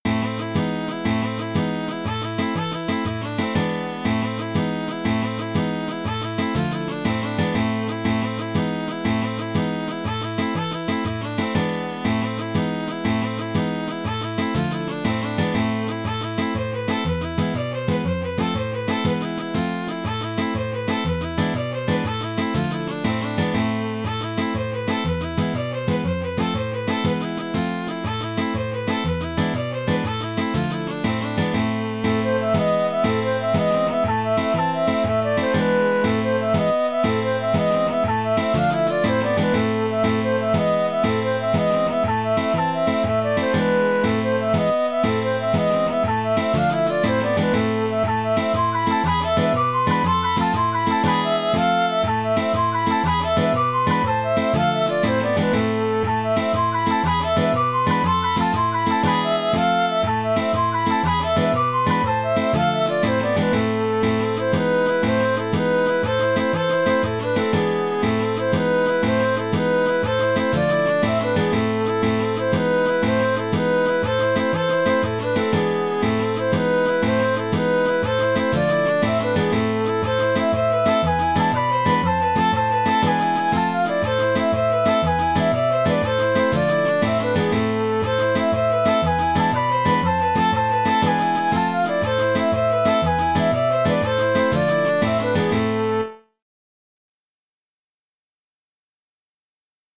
L'essentiel est dans le rythme.